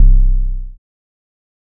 808 (Classic).wav